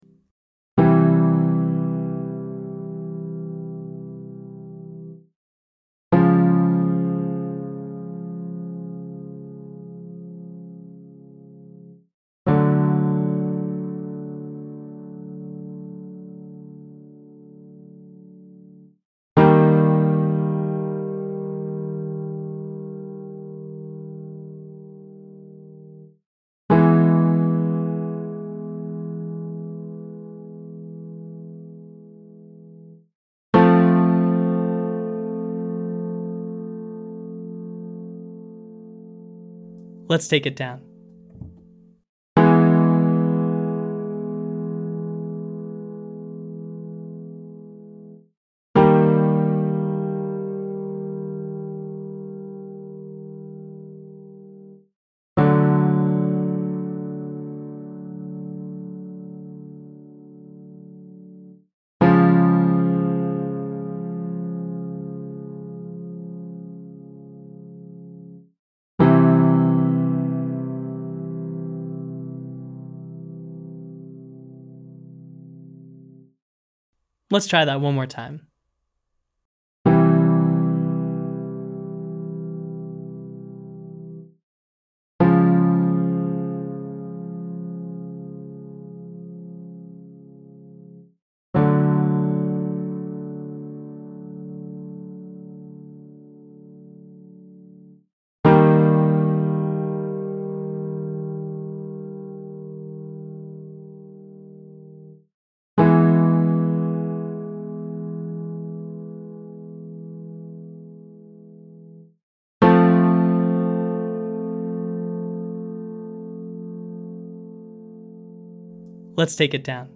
Singing Without A Guide - Online Singing Lesson